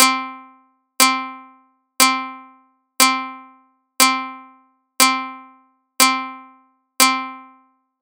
8_clicks_20ms.mp3